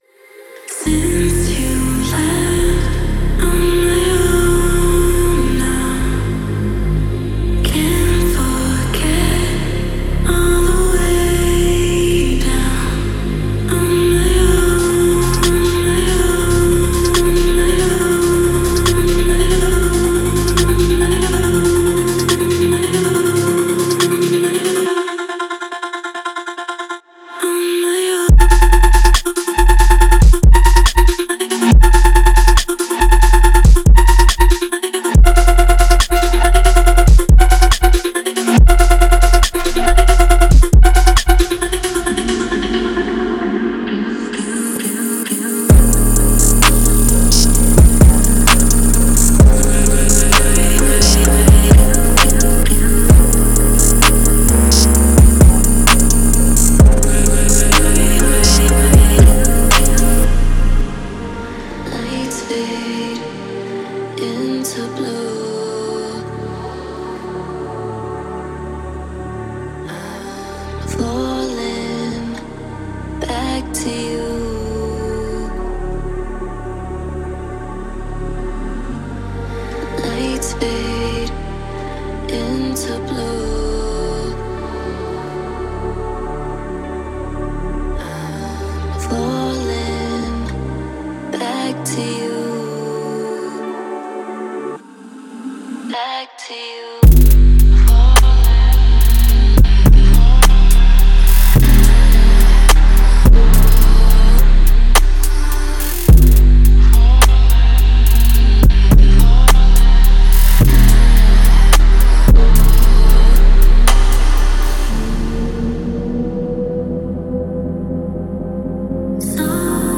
Genre:EDM
本パックには、クリーンでミックス即対応の280以上のボーカルを収録しています。
ボーカルメロディ
スポークンワード
メロディックハウス、ダンス、フューチャーポップ、メインステージを含むEDMに最適です。
デモサウンドはコチラ↓